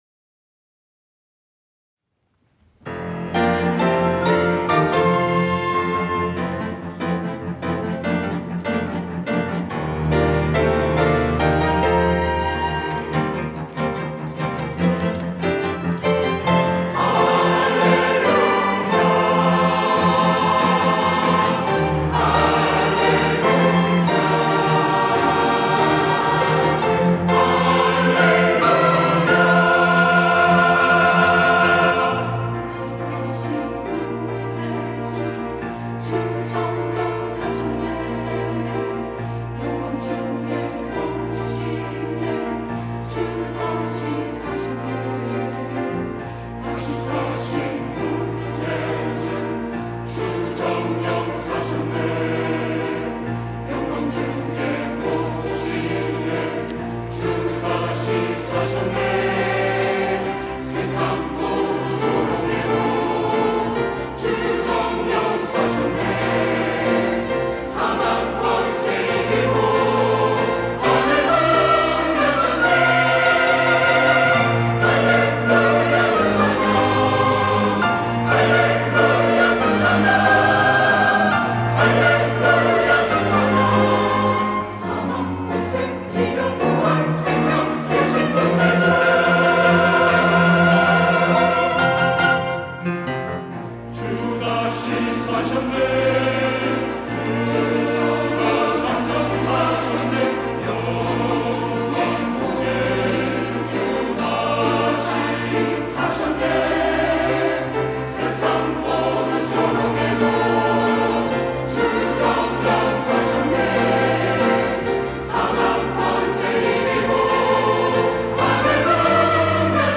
호산나(주일3부) - 다시 사신 구세주
찬양대